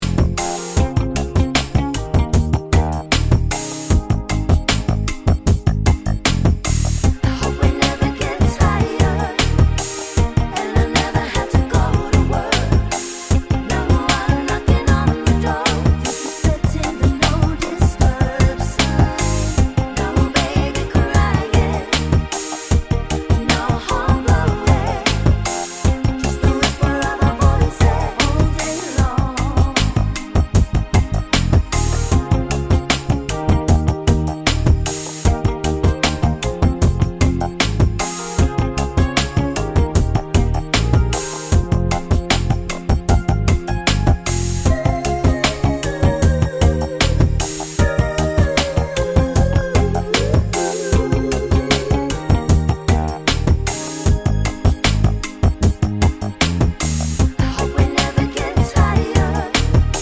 no Backing Vocals Reggae 4:26 Buy £1.50